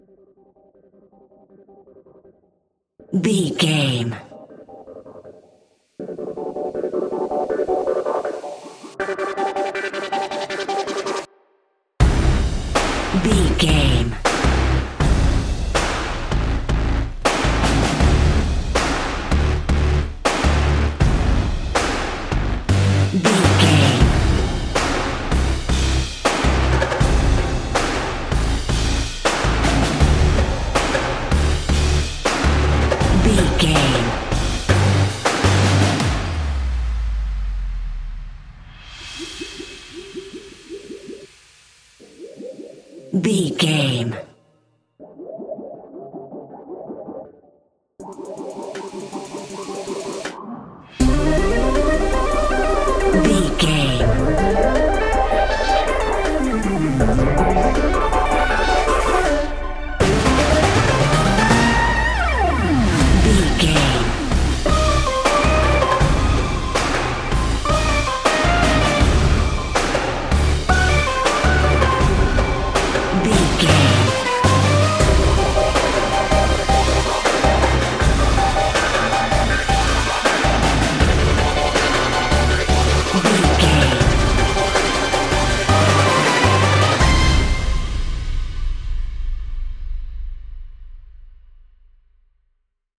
Epic / Action
Fast paced
In-crescendo
Aeolian/Minor
aggressive